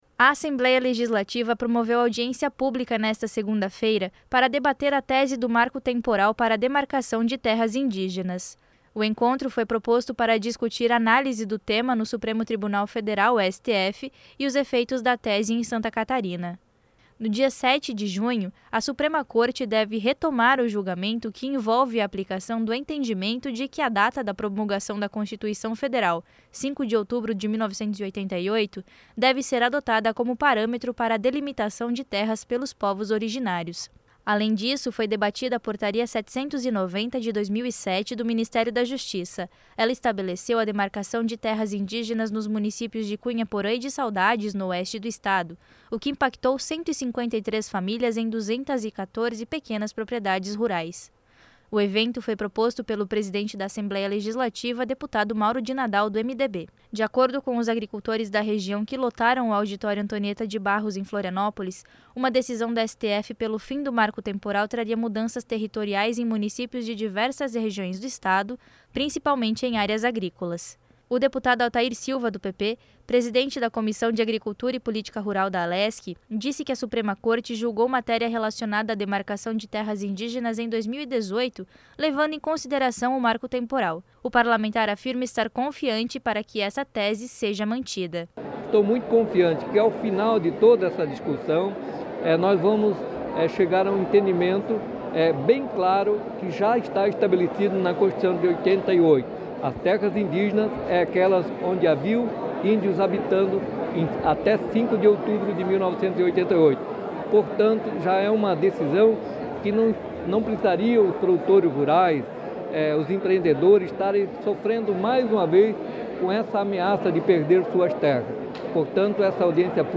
Entrevistas com:
- deputado Altair Silva (PP), presidente da Comissão de Agricultura e Política Rural;
- deputado Marcos Vieira (PSDB);
- senador Jorge Seif (PL/SC).